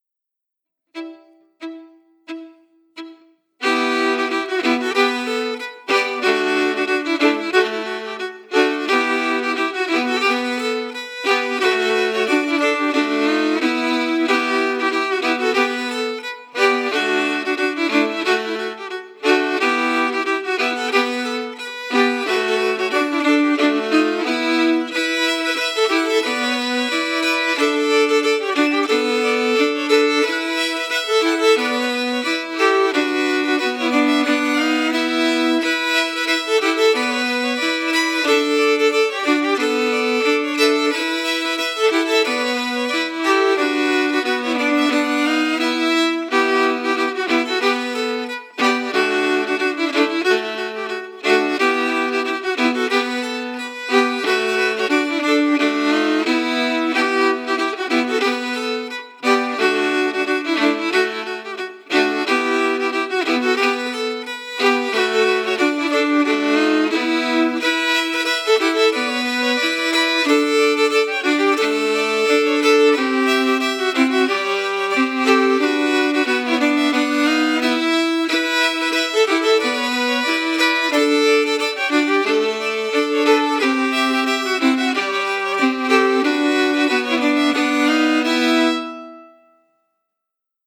Key: Em
Form: March
Harmony emphasis
Genre/Style: “Northern March”